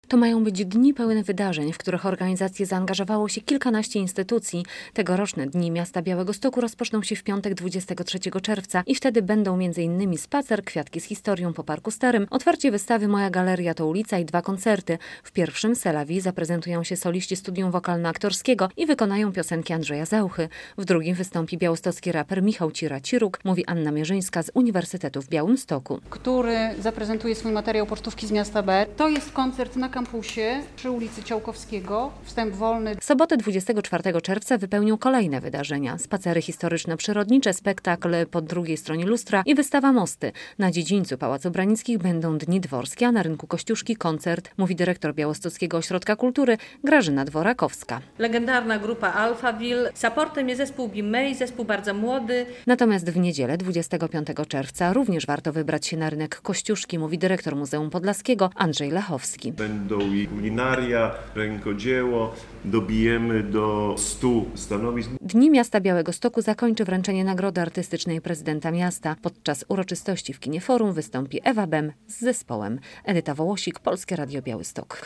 Dni Białegostoku 2017 - co w planie? - relacja